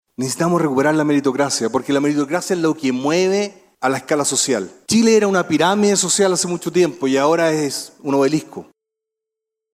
Sin mayores polémicas se desarrolló el panel presidencial en el Encuentro Nacional de la Empresa (Enade) 2025, en el que participaron siete de los ocho candidatos que buscan llegar a La Moneda.
Palabras finales de los candidatos
Comenzó el candidato del Partido de la Gente, Franco Parisi, quien apuntó a “recuperar la meritocracia“.
223-cu-presidencial-franco-parisi.mp3